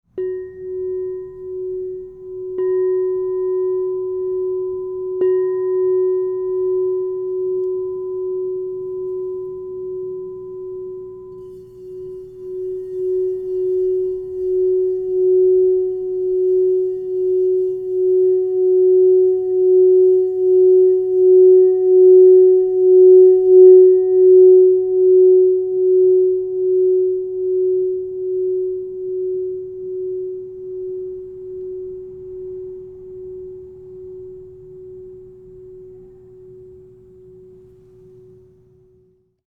Aqua Aura Gold 6" F# +5 - Divine Sound
Behold our latest masterpiece: the 6-inch F# True Tone